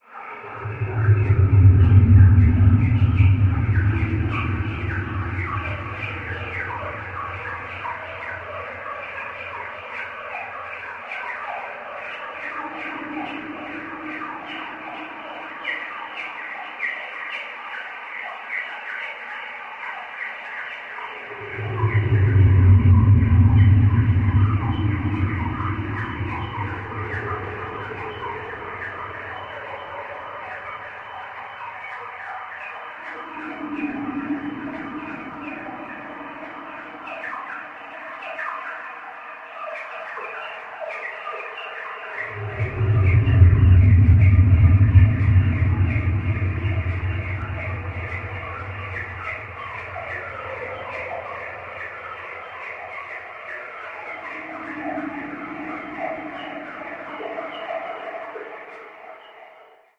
Birds underwater quality with higher pitch chirp effects